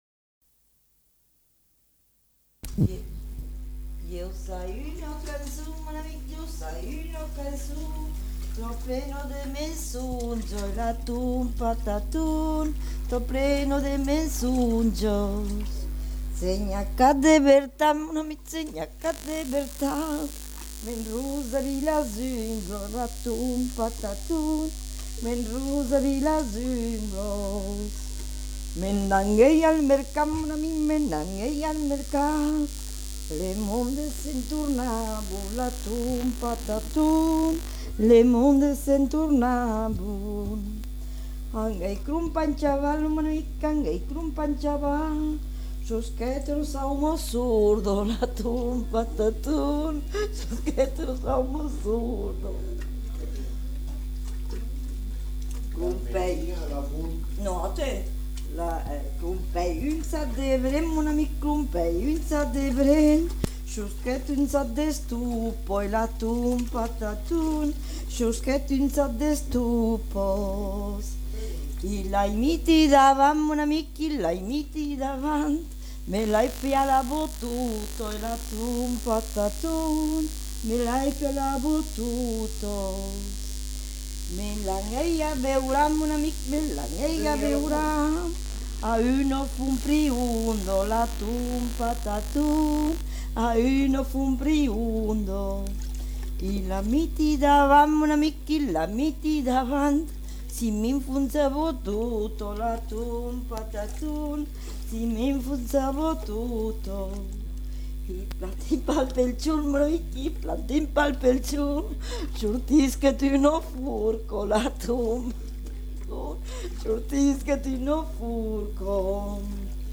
Aire culturelle : Lauragais
Lieu : Lanta
Genre : chant
Effectif : 1
Type de voix : voix de femme
Production du son : chanté
Classification : chanson de mensonges